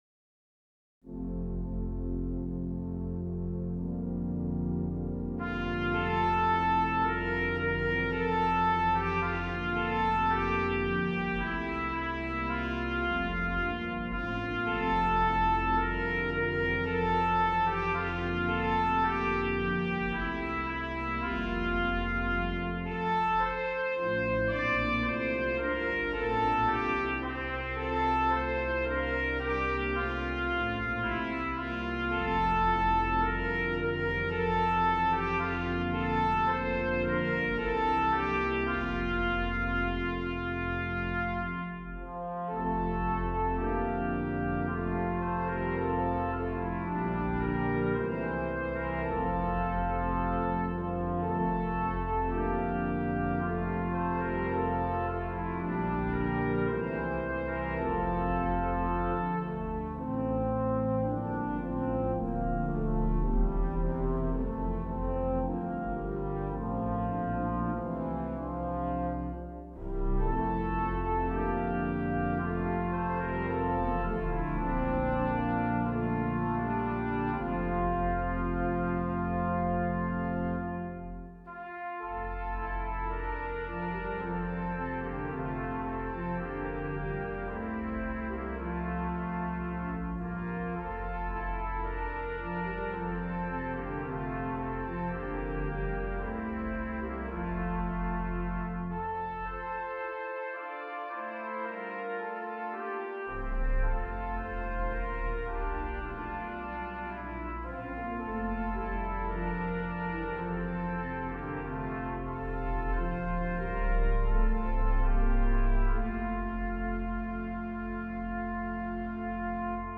Voicing: Brass Trio with Organ